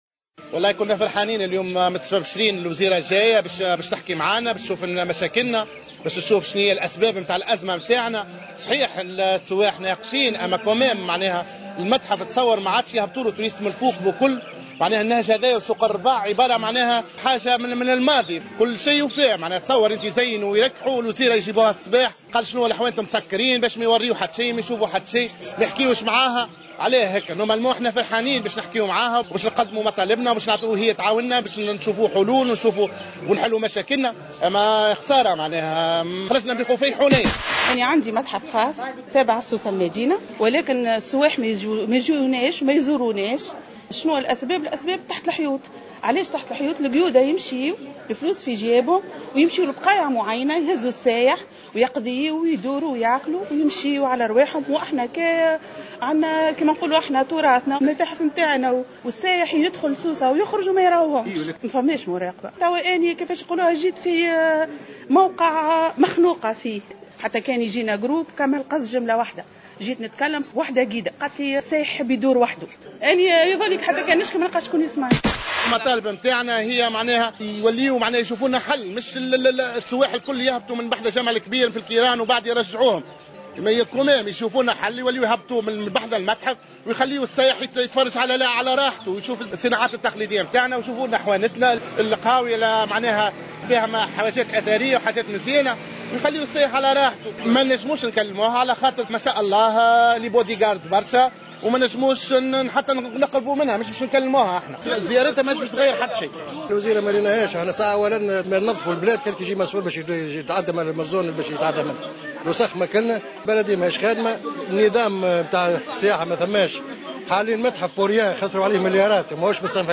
تصريحات التجار